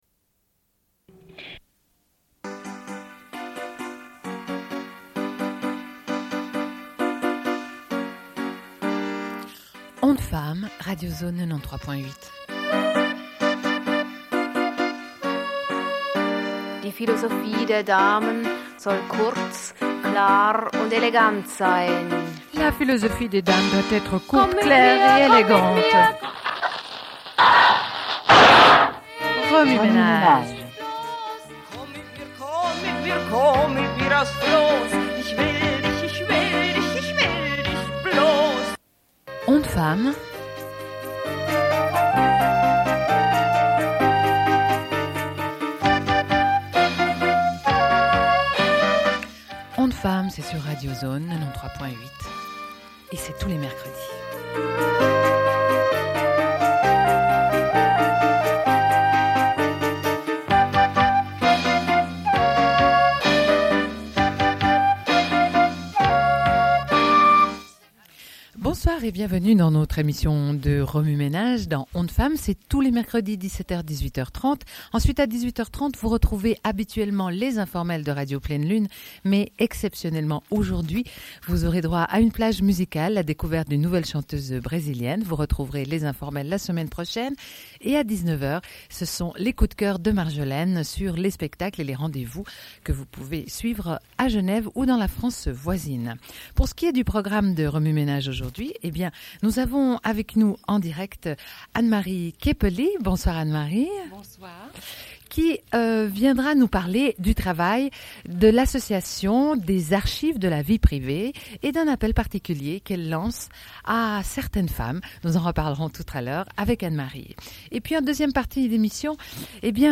Une cassette audio, face A
Radio